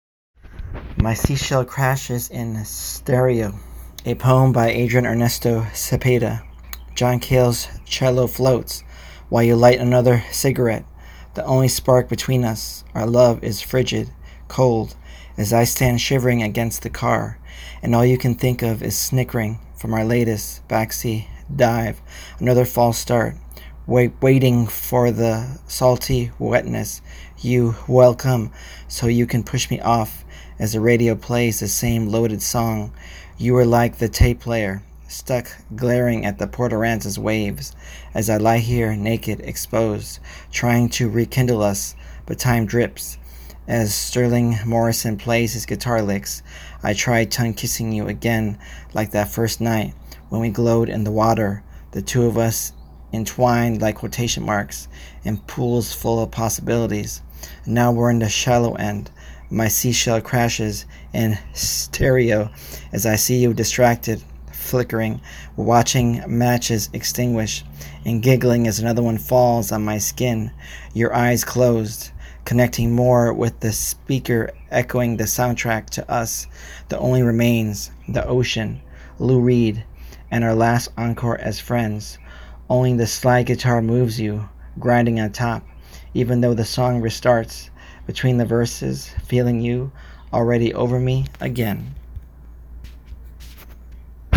Poem
my-seashell-poem.m4a